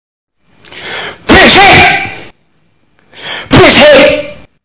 brisik Meme Sound Effect